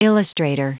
Prononciation du mot illustrator en anglais (fichier audio)
Prononciation du mot : illustrator